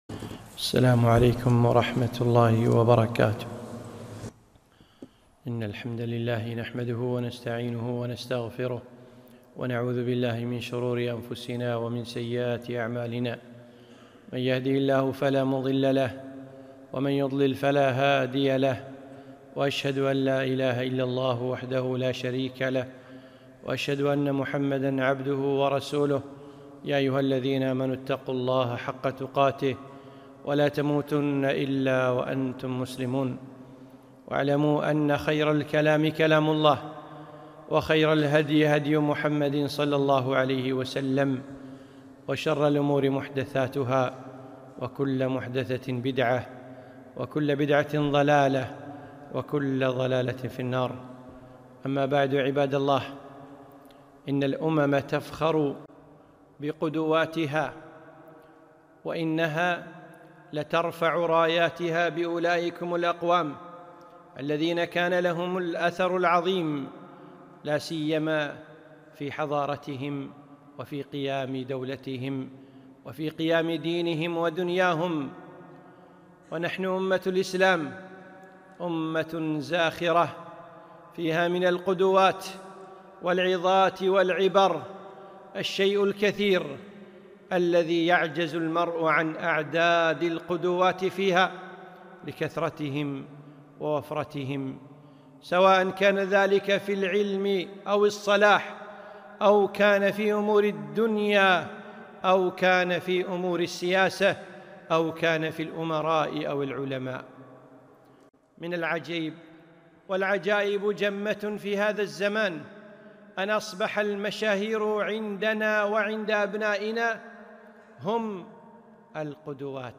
خطبة - خيار الأمة